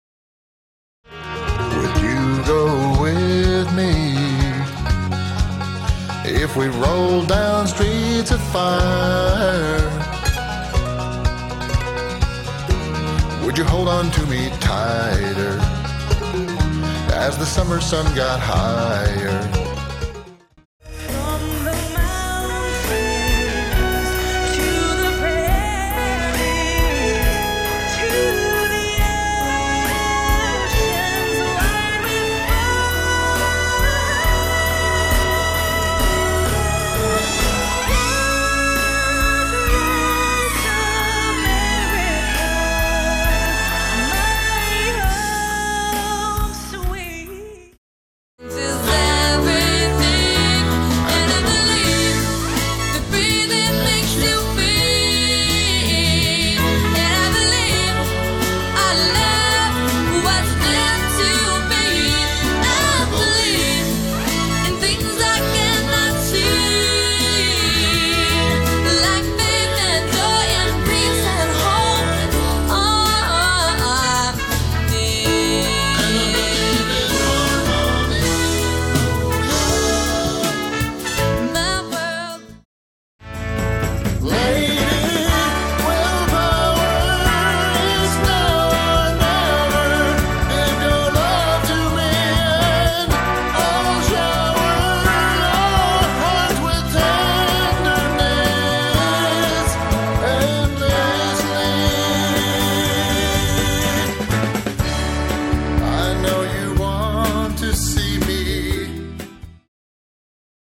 Just for grins here's a quick demo of some stuff I've done with an MXL 990 . The male solo vocals, male harmonies and trumpet stuff was all done with a 990, added to other mixes/backing tracks. Attachments 990_demo.mp3 990_demo.mp3 2.5 MB · Views: 71